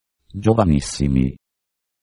I made the soundfiles with the text-to-speech program at: